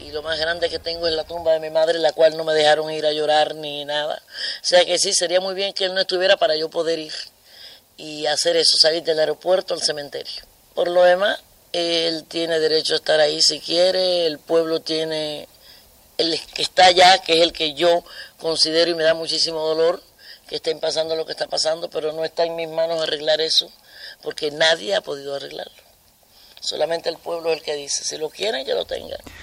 Entrevista a la cantant cubana Celia Cruz que presentava el seu disc "Azúcar negra"